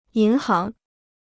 银行 (yín háng) Free Chinese Dictionary